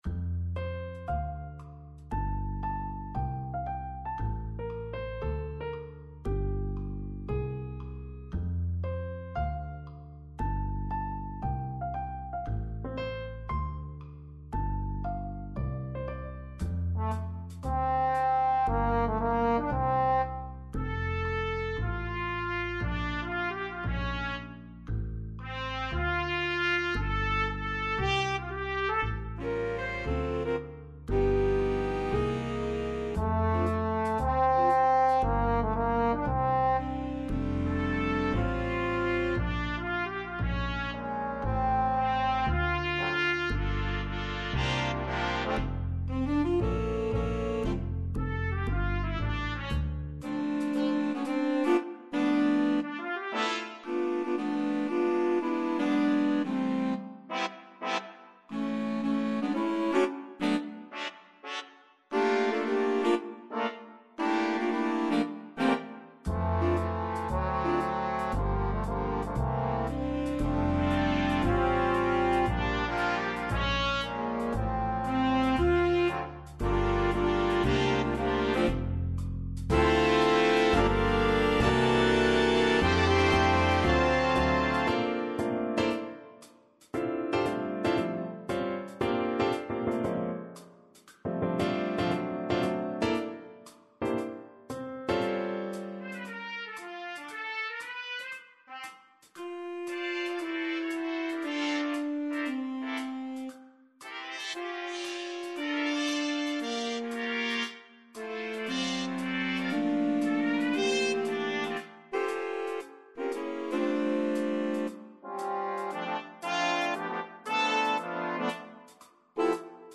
Skladby pro Big Band / Big Band Scores
computer demo